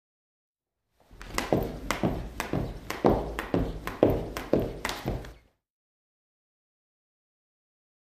Cardiovascular Exercise; Jumping Rope. Short.